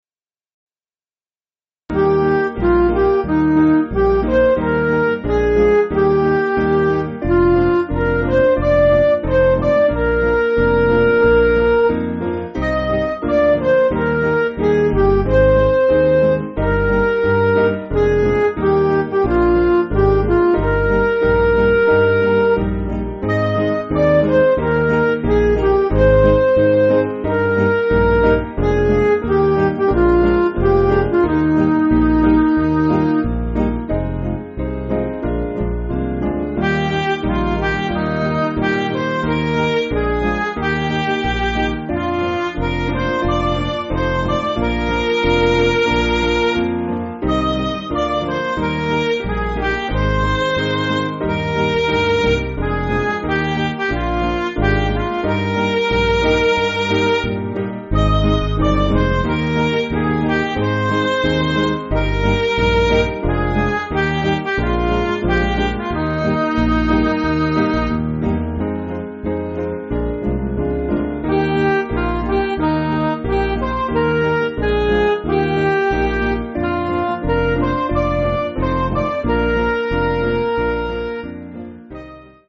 Hymn books
Piano & Instrumental
(CM)   4/Eb